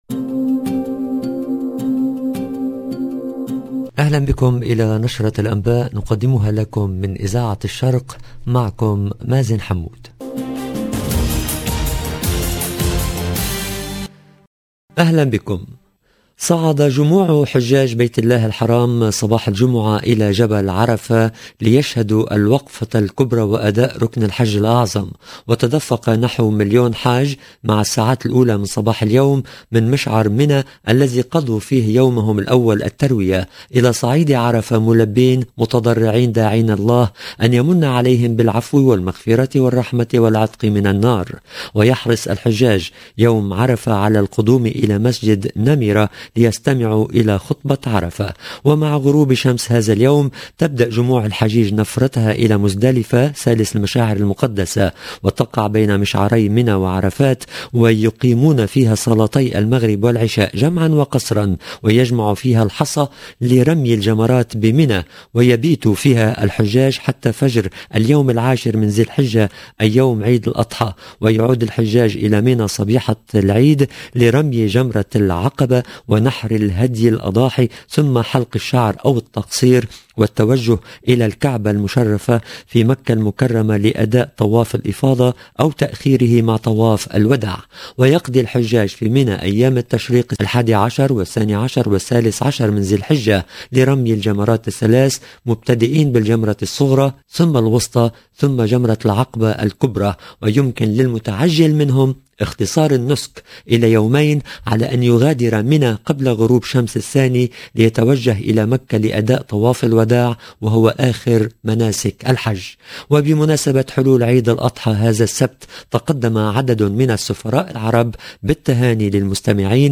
LE JOURNAL DU SOIR EN LANGUE ARABE DU 8/07/22